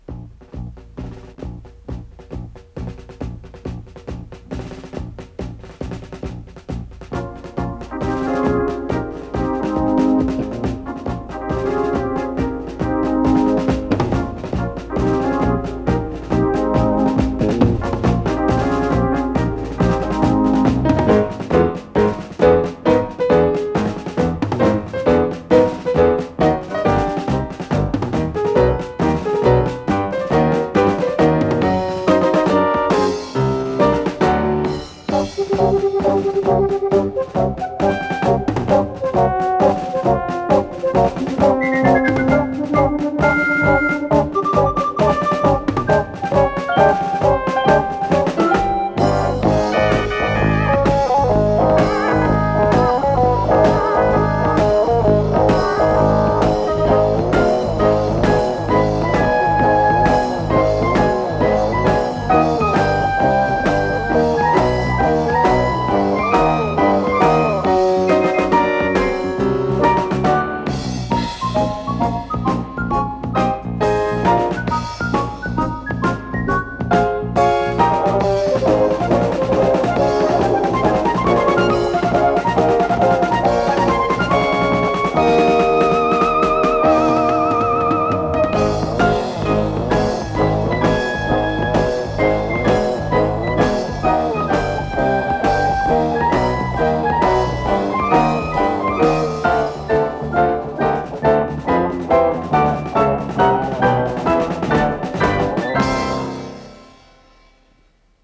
ВариантДубль моно